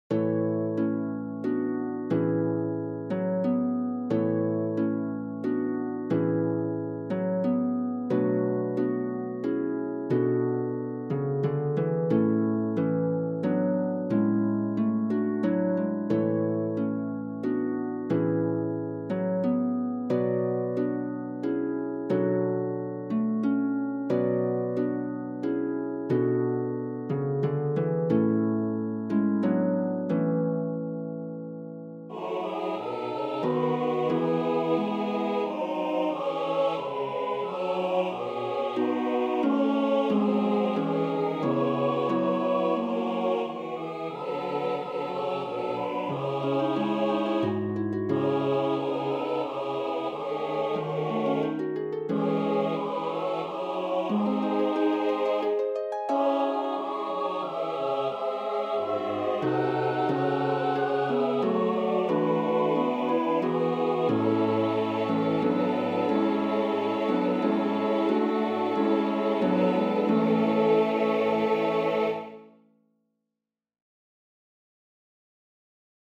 SATB, Harfe